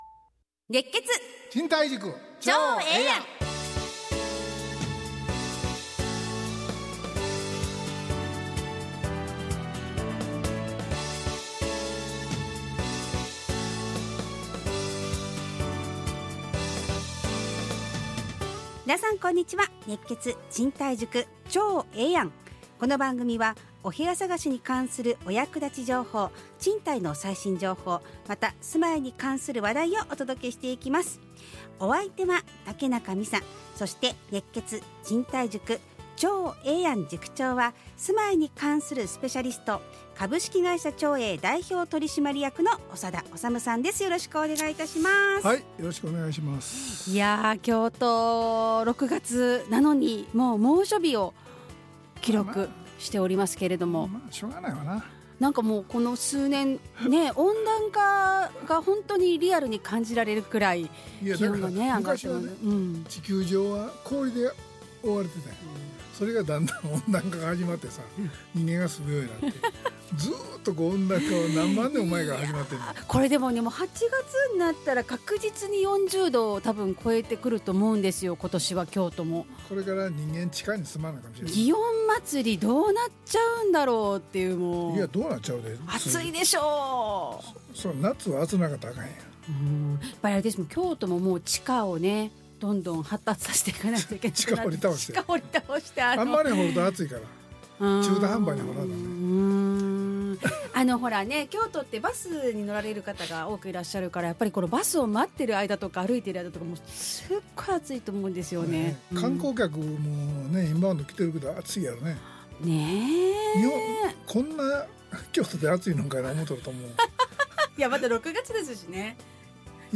ラジオ放送 2025-06-20 熱血！